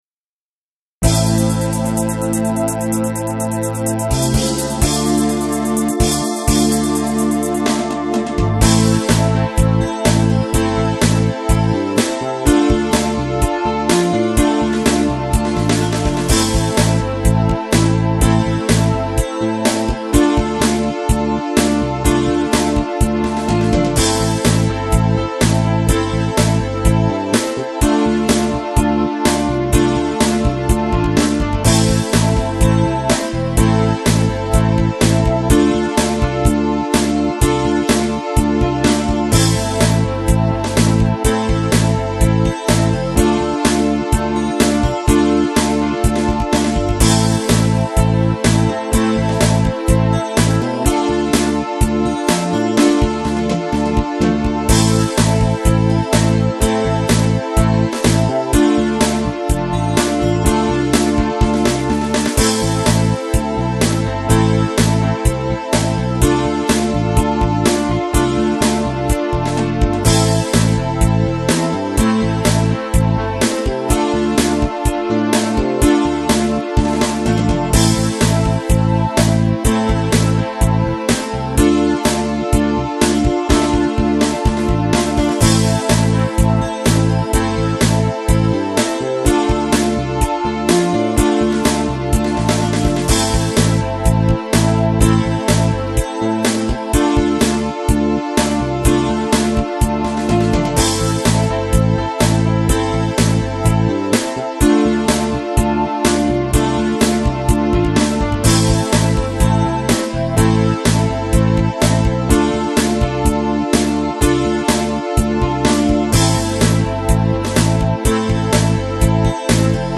MP3s are 96Kbps, but they sound quite good.
Am/Dm upbeat Rock.